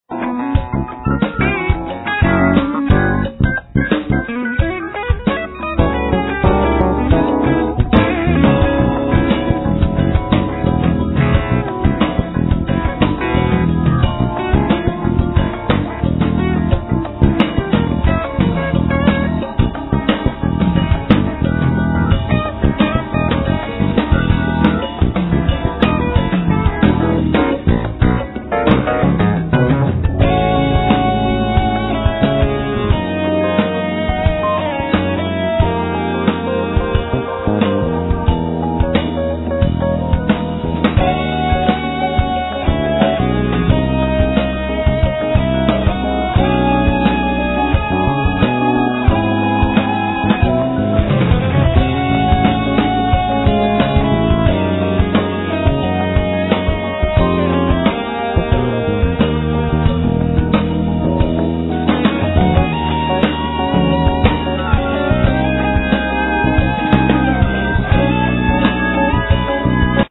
Strings:
Vocals
Guitar synthes,Ac.guitar samples
Fender piano,Djembes Egg,Synthes
Double. bass
Drums